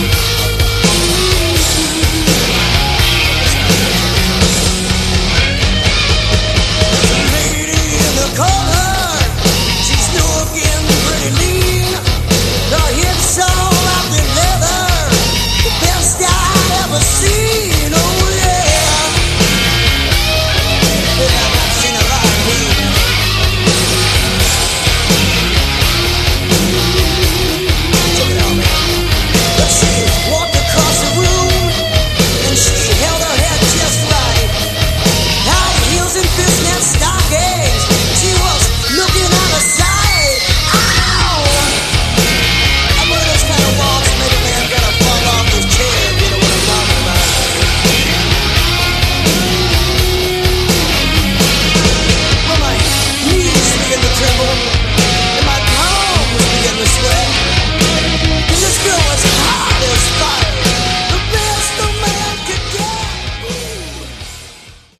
Category: Hard Rock
lead and backing vocals
electric and acoustic guitars
bass
drums